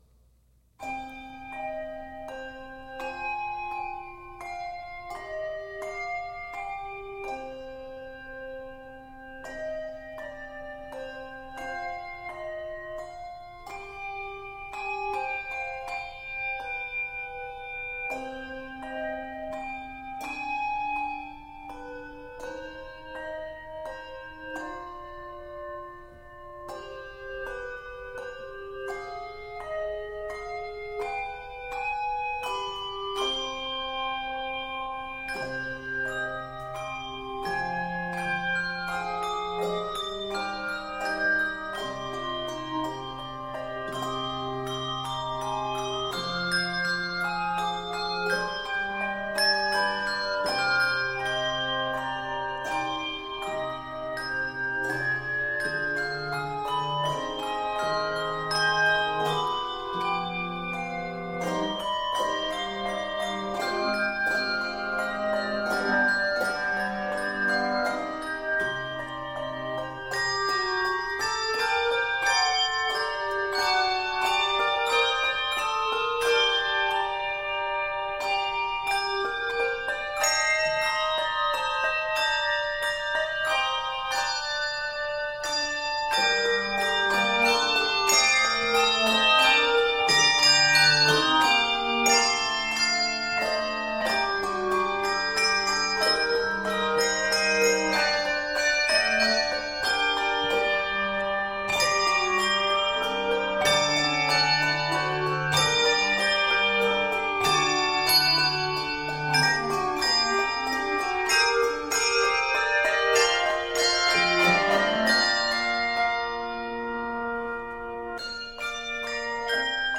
N/A Octaves: 3-6 Level